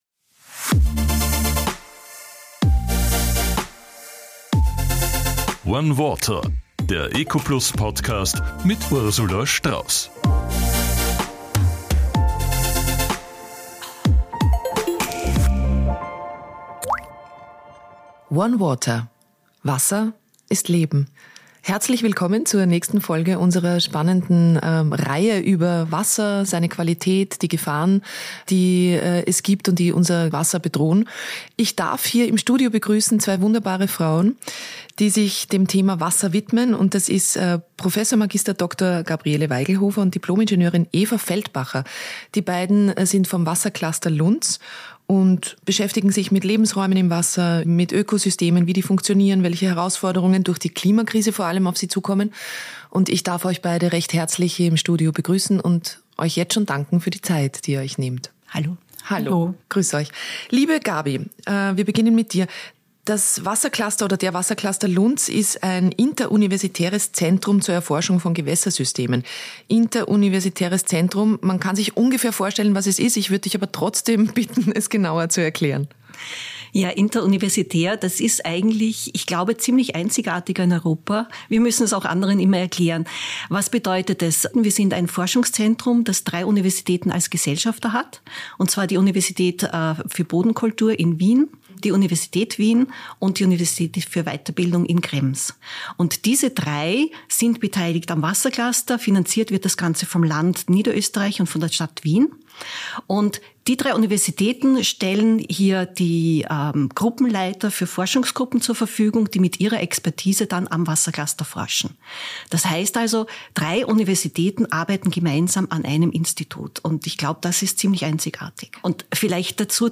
Beschreibung vor 1 Woche ONE WATER – Wasser ist Leben In dieser Folge von ONE WATER begrüßt Ursula Strauss zwei Wissenschaftlerinnen vom WasserCluster Lunz am See.